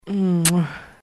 Звуки поцелуя
Шум страстного поцелуя между влюбленными